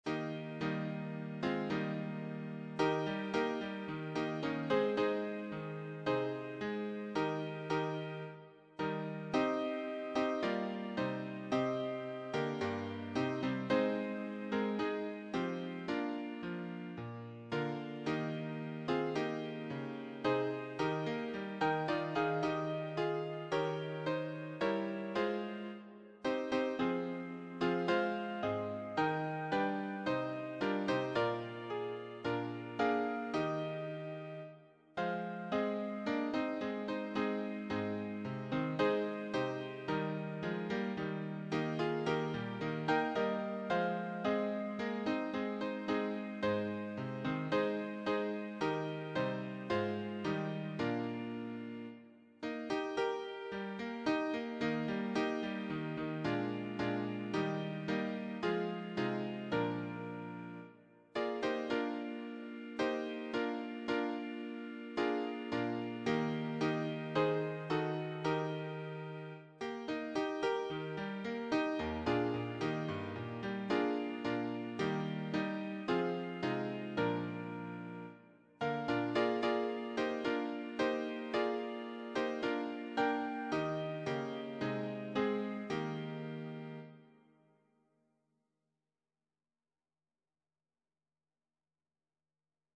choir SATB